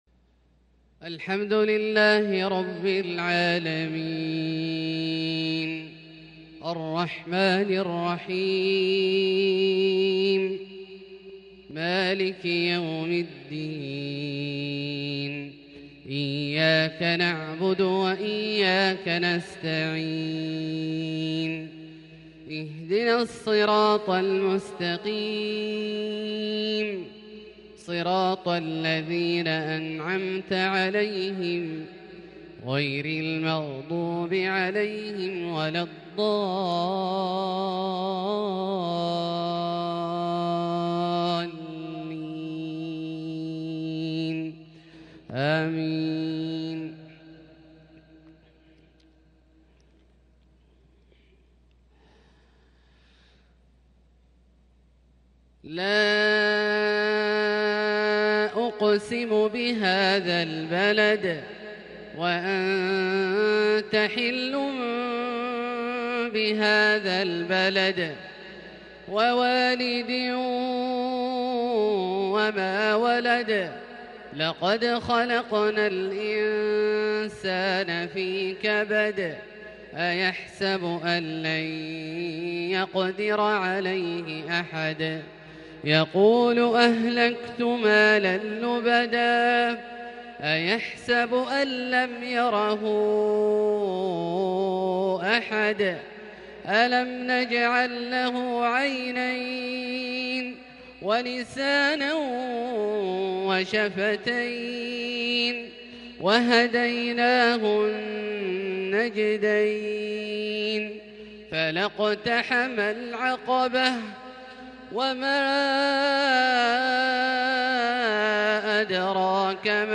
عشاء 7-6-1442هـ | تلاوة إبداعية لسور البلد والشمس > ١٤٤٢ هـ > الفروض - تلاوات عبدالله الجهني